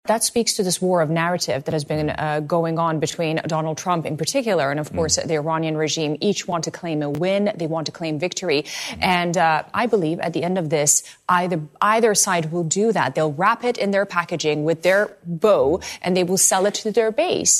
Middle East Commentator